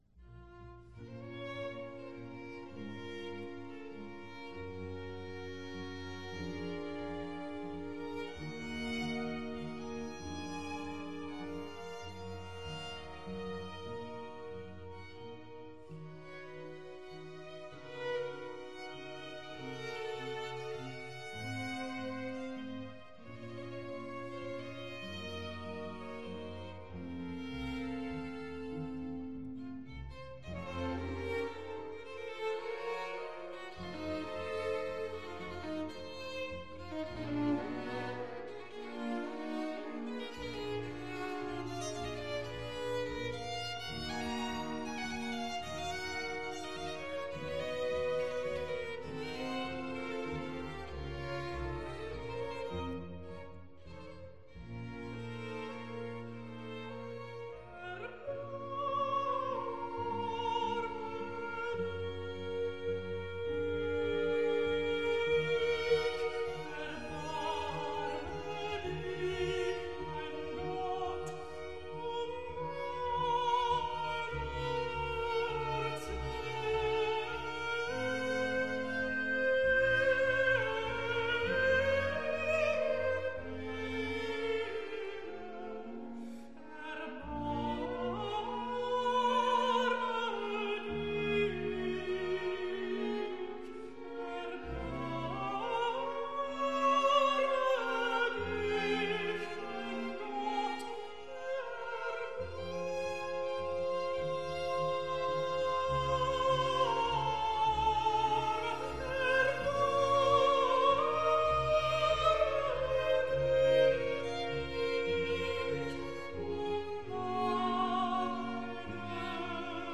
音乐规模很大，使用了三个合唱 队、两个各由17件乐器组成的管弦乐队、两座管风琴，外加独唱者和独奏乐器。